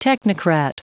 Prononciation du mot technocrat en anglais (fichier audio)
Prononciation du mot : technocrat